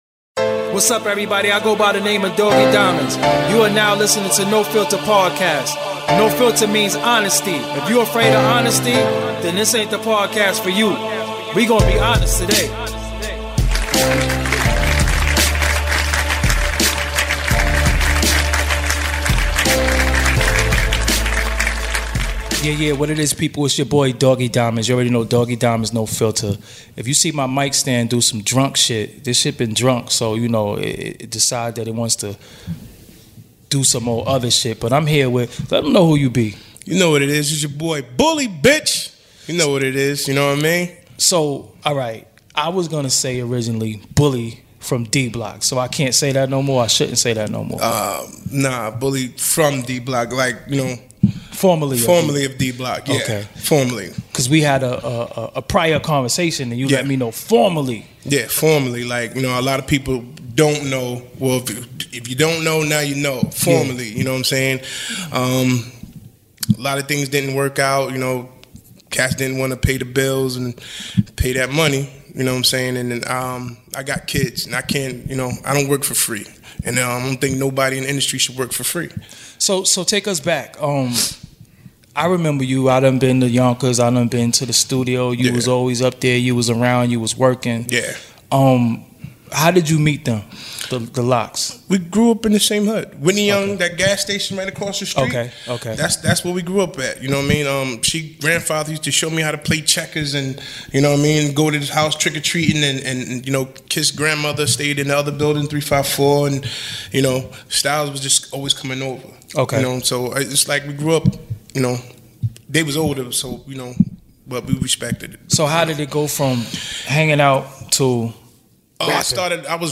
(Full Interview)